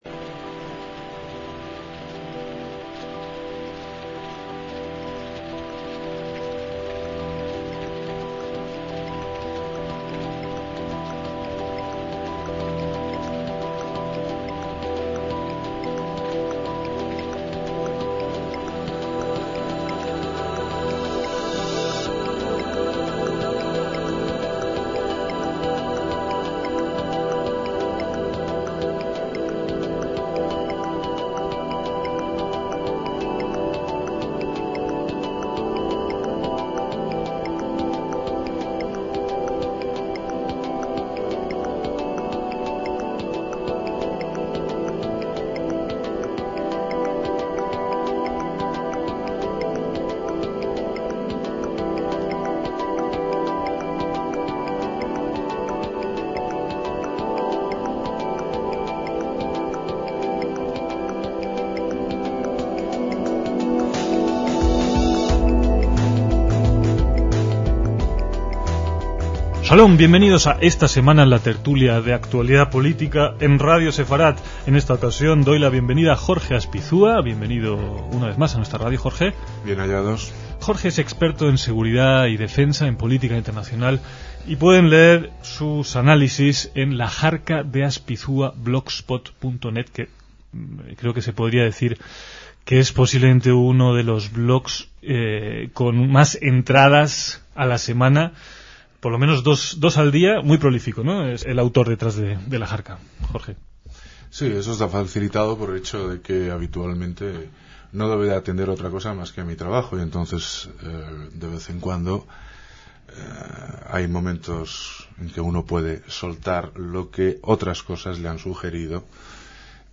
tertulia semanal